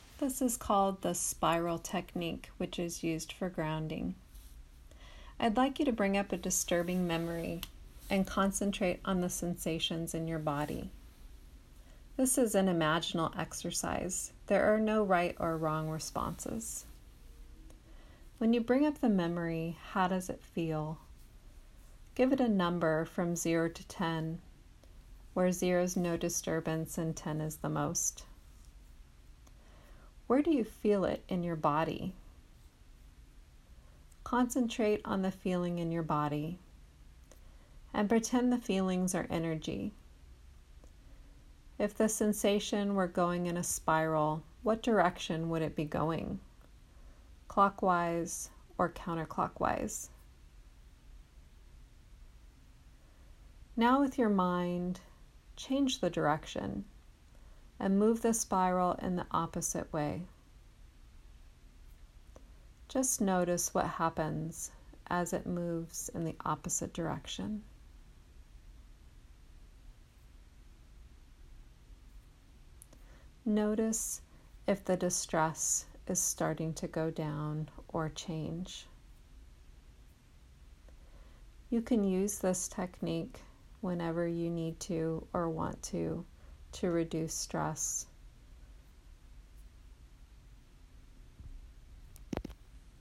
Please allow yourself to get comfortable as I lead you through a Grounding Spiral Technique.